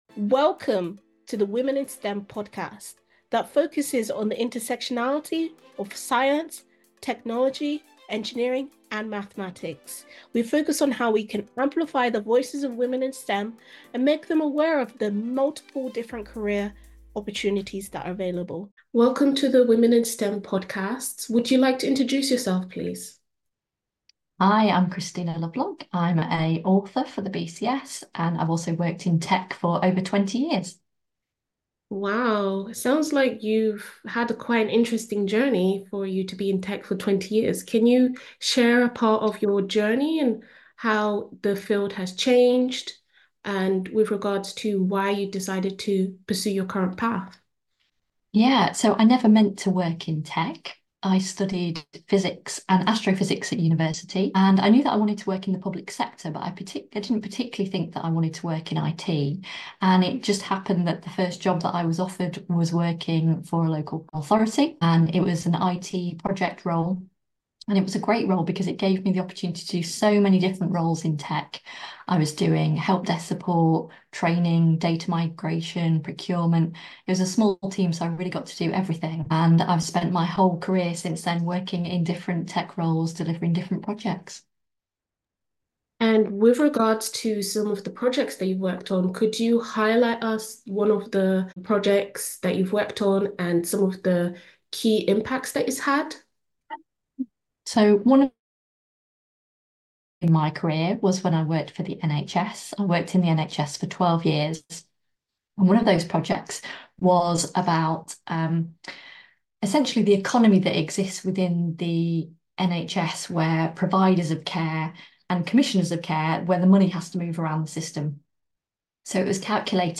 Dive into an electrifying conversation